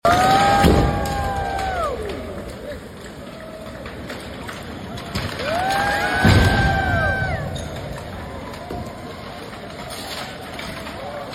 TBM breakthrough in the City sound effects free download